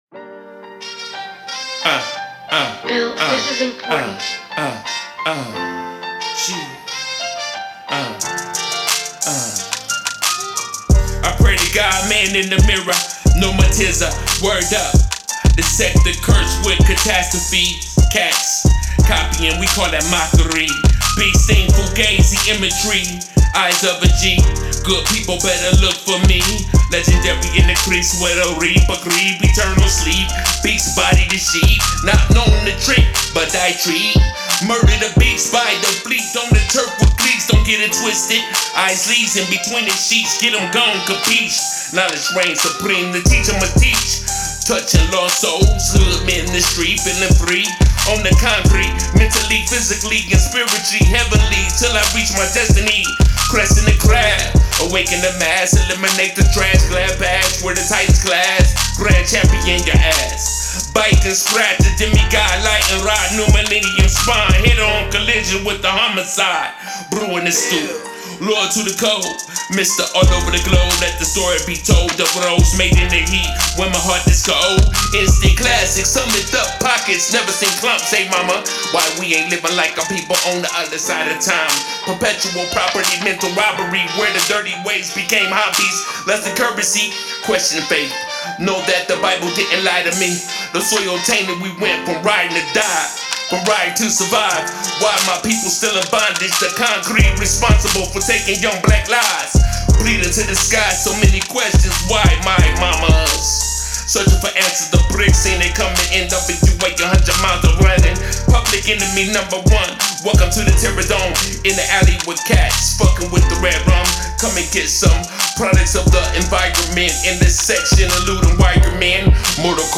Rap
Description : HIPHOP LOVE